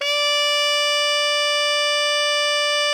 ALTO  FF D 4.wav